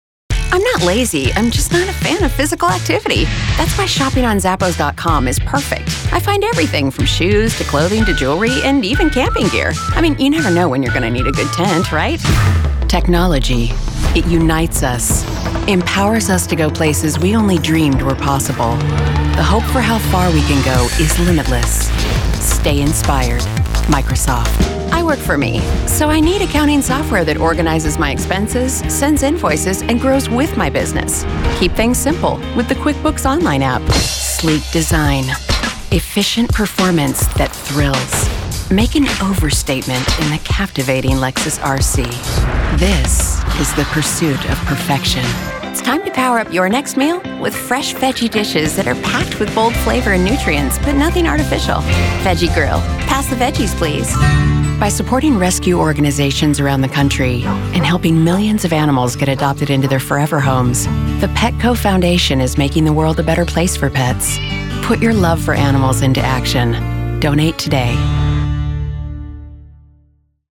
Warm, friendly and personable, as well as authoritative, confident and professional – throw in a little playful sarcasm, and you have my voice. I can perform a wide range of characters, from an energetic child to a soccer mom for commercials, animation, games/toys, jingles and more.